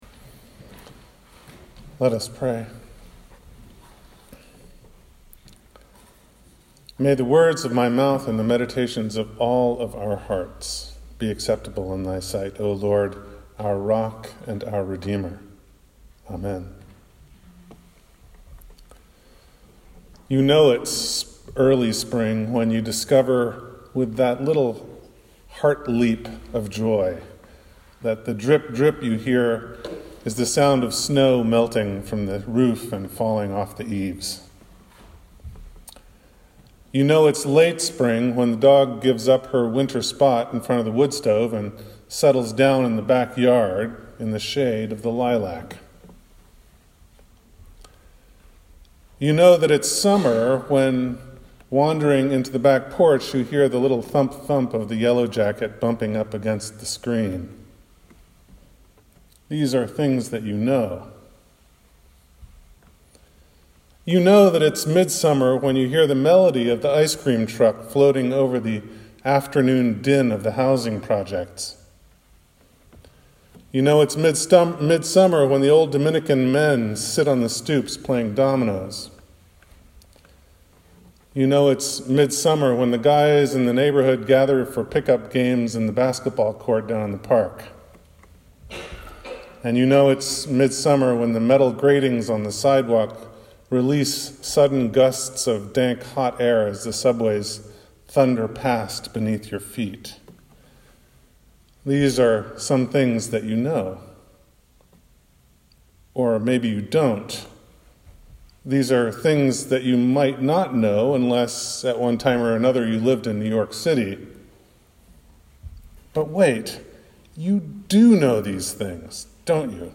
If you would like to hear this sermon as preached from the pulpit, please press the play button below:
sermon6219.mp3